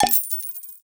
bonus_coin_1.wav